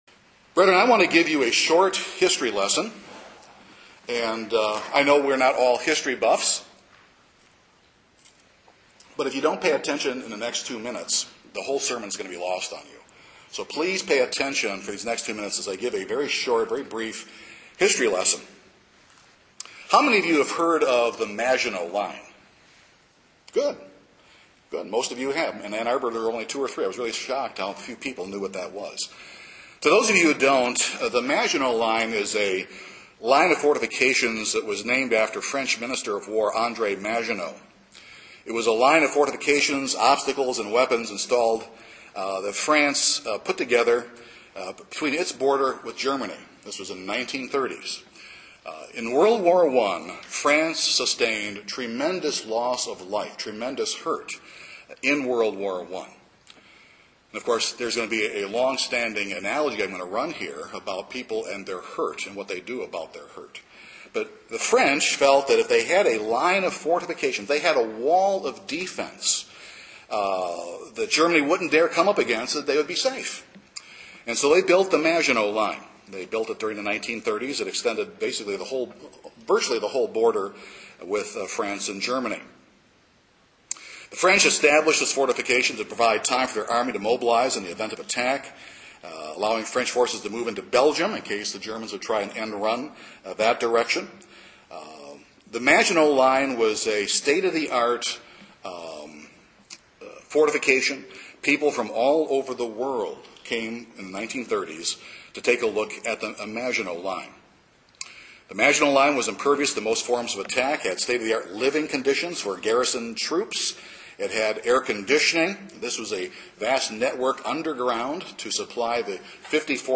Today’s sermon shows what can happen when we rely on our strength.